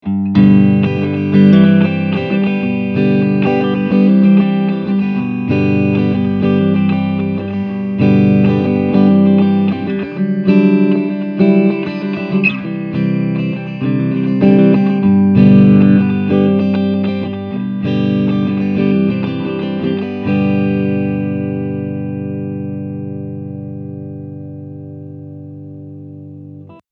Der klare Sound klingt ansprechend.
Der integrierte Federhall klingt ansprechend und verleiht dem Klang bei Bedarf mehr Räumlichkeit.
Klangbeispiele mit dem KONG SolidThirty
Clean
• Gitarre: Gibson Les Paul Special
• Gitarrenverstärker: KONG SolidThirty – mit etwas vom integrierten Federhall
• Mikrofon: Shure SM57
kong_solidthirty_test__clean.mp3